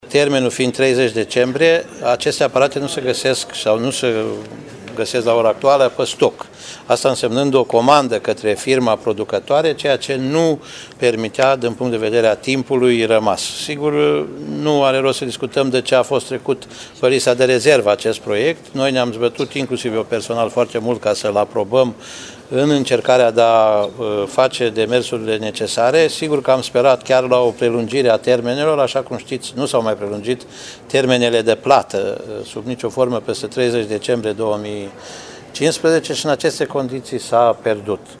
Executivul Consiliului Județean Timiș a prezentat un raport cu explicații pentru pierderea finanțării europene prin care Spitalul Județean Timișoara ar fi trebuit modernizat cu aparatură de 10 milioane de euro. Finanțarea a fost pierdută pentru că nicio firmă nu s-a prezentat la licitație, iar potrivit președintelui CJ Timiș, Titu Bojin, firmele nu au venit la licitație pentru că termenul de achiziție a echipamentelor era prea scurt: